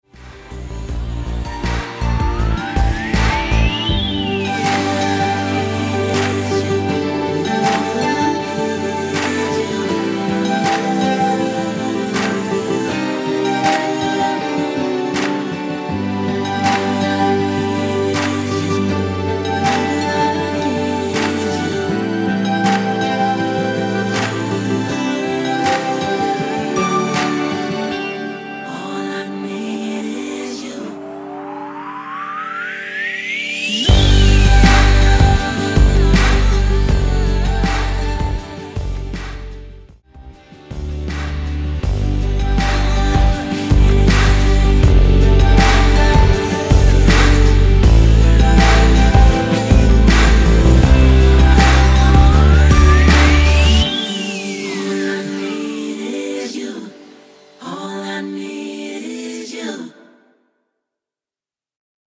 코러스 포함된 MR 입니다.(미리듣기 참조)
앞부분30초, 뒷부분30초씩 편집해서 올려 드리고 있습니다.
중간에 음이 끈어지고 다시 나오는 이유는